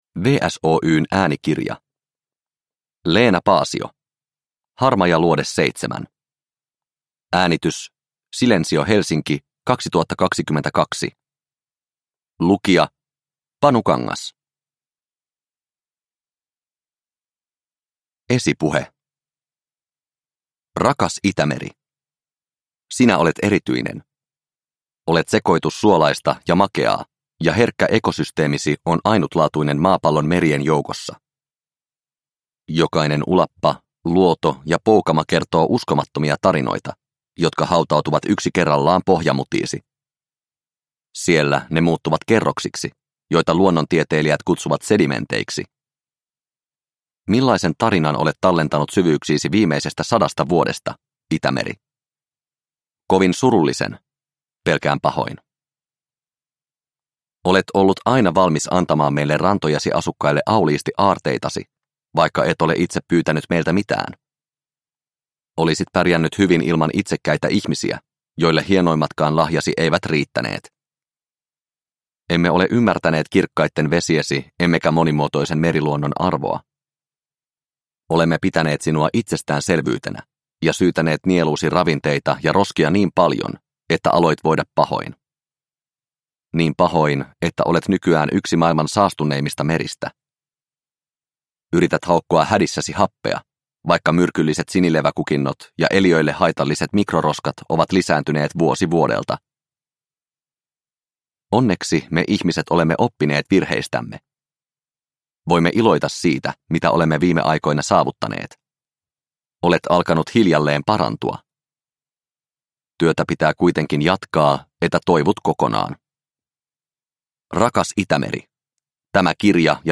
Harmaja luode seitsemän – Ljudbok